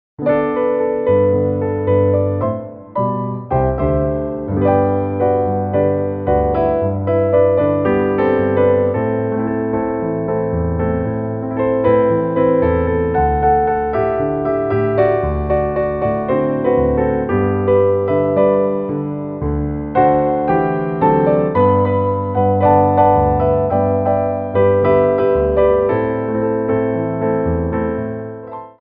4/4 (16x8)